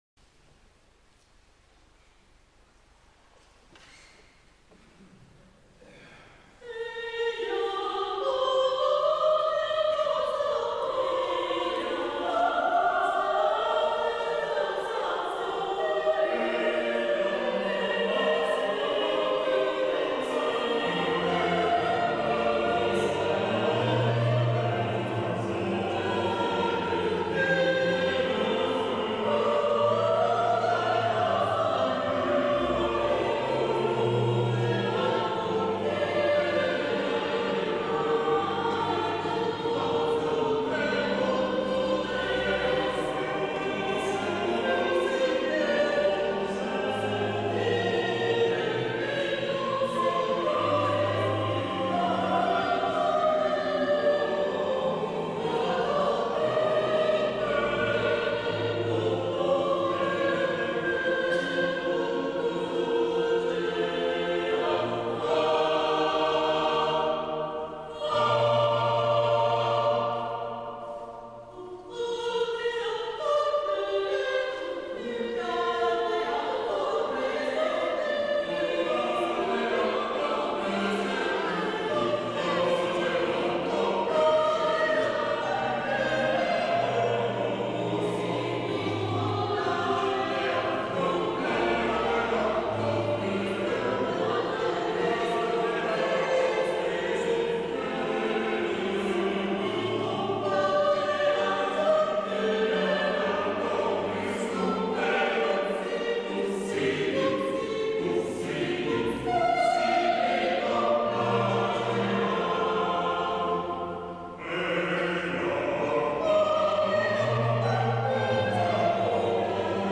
Choir Music